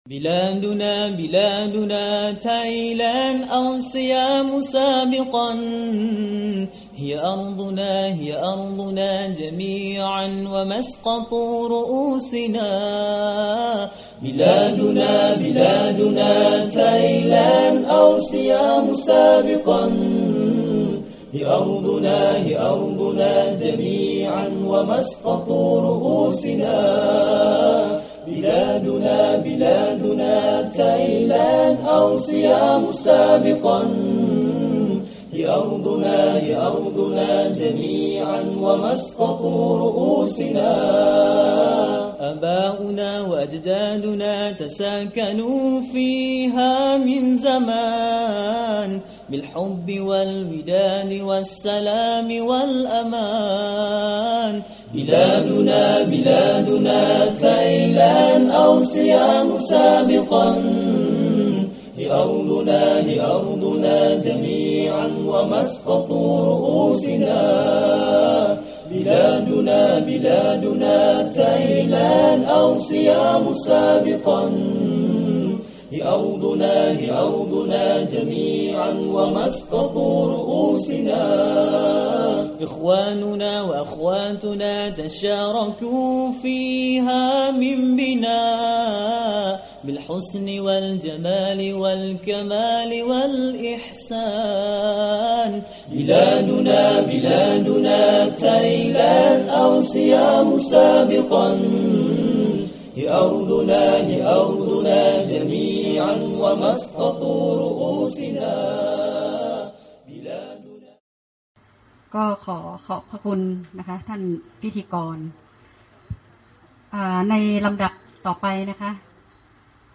มัสยิดอัลฟะลาห์ มัสยิดกลางจังหวัดลำปาง